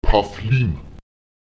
Lautsprecher 2’000 [paTÈlim] 1024